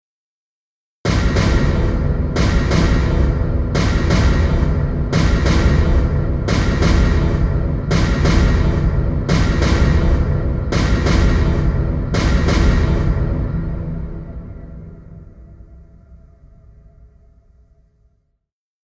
creepyhits2.wav